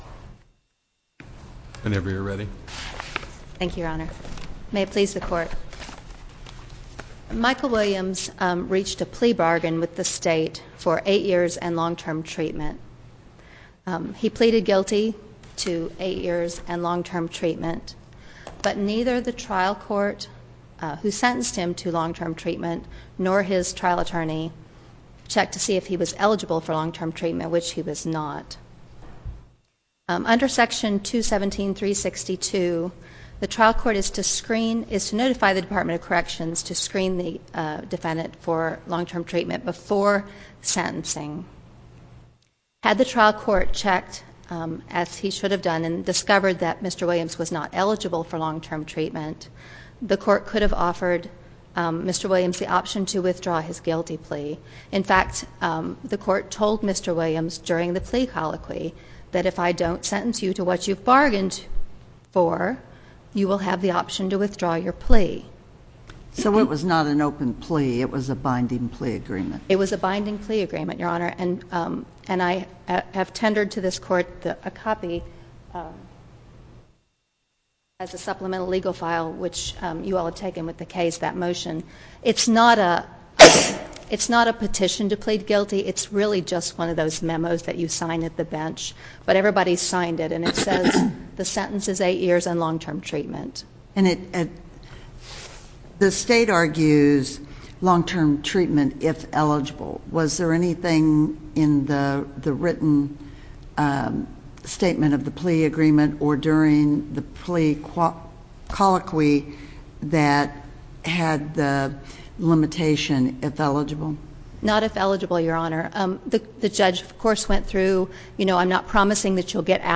link to MP3 audio file of oral arguments in SC97287